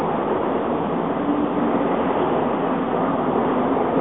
Silny vietor - dokola.wav